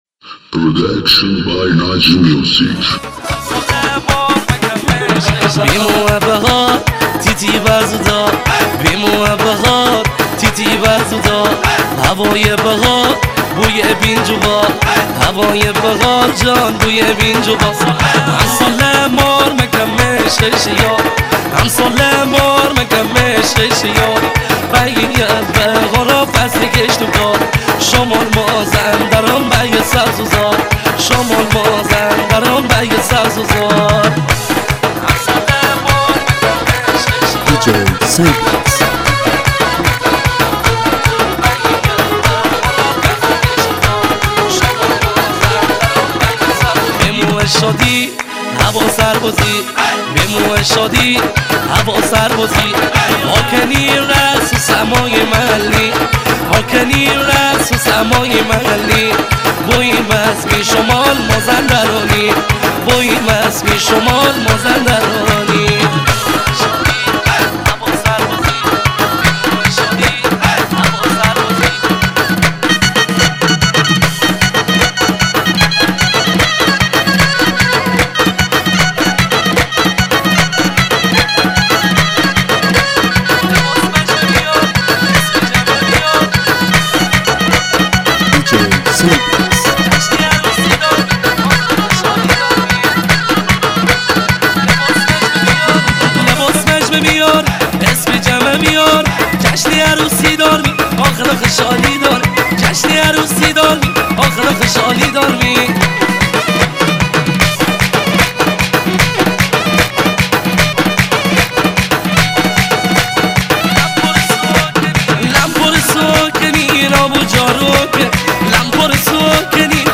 ریمیکس محلی مازندرانی